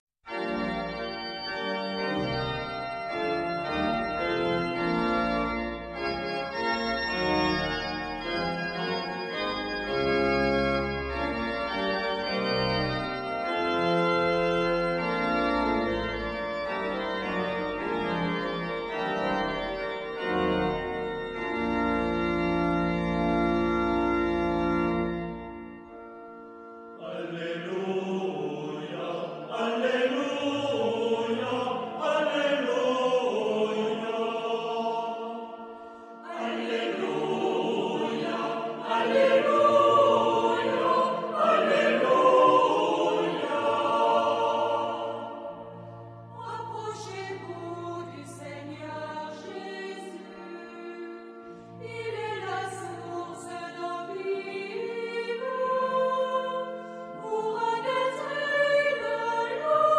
Genre-Style-Forme : Cantique ; Sacré ; Acclamation
Type de choeur : SATB  (4 voix mixtes )
Tonalité : si bémol majeur